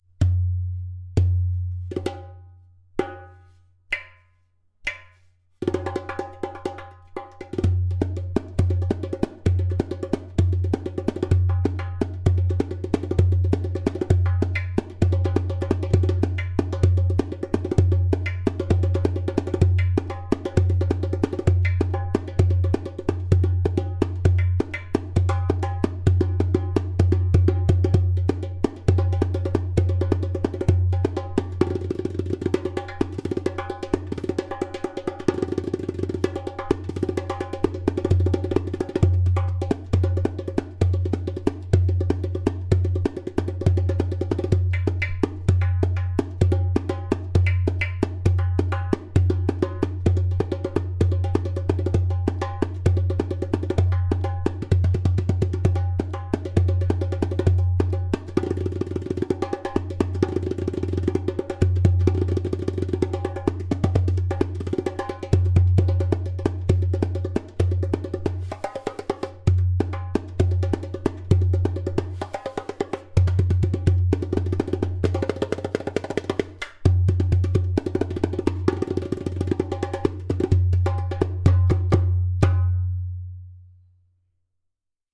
Premium Deepsea Dohola 10 inch with Fish skin
In this line of darbukas materials like clay, glaze and natural skin met in a magical way which brings into life a balanced harmonic sound.
• Strong and easy to produce clay kik (click) sound
• Skin: Fish skin